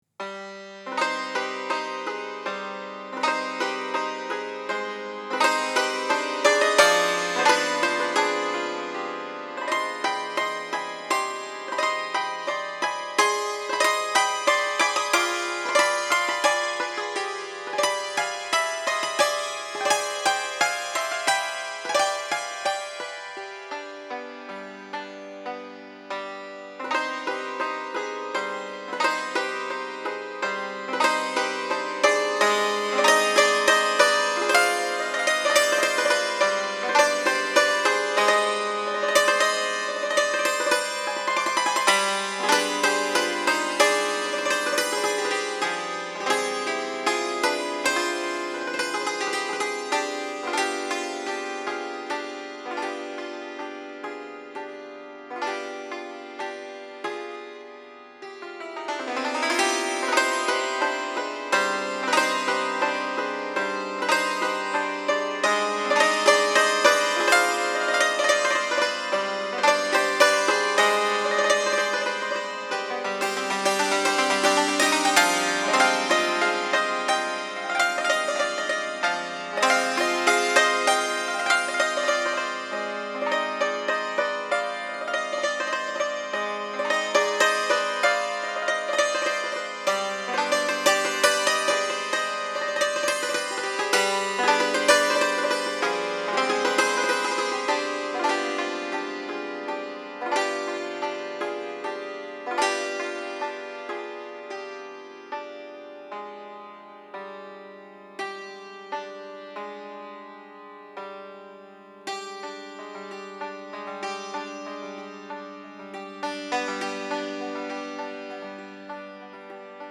آرامش بخش بومی و محلی ملل موسیقی بی کلام
تکنوازی سنتور